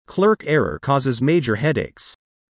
以下、設問１）〜４）の不正解答案文を音読したネイティブ音声を出題しました。
不正解答案の読み上げ音声
▶ 「clerical（形）事務上の」と「clerk（名）事務職員」の音を聴き分けよう！